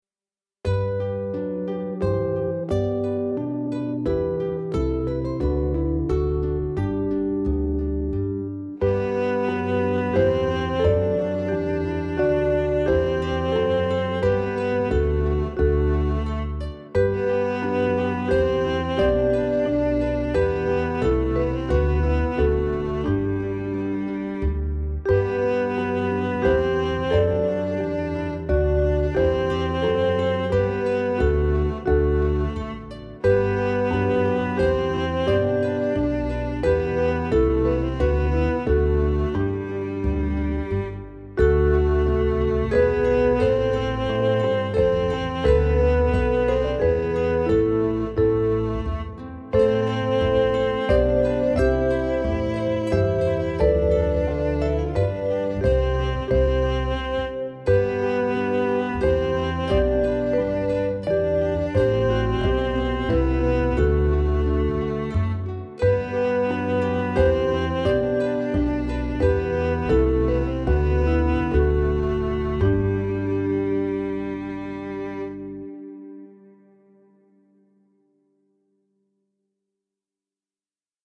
Jezus, diepe vreugd: langzaam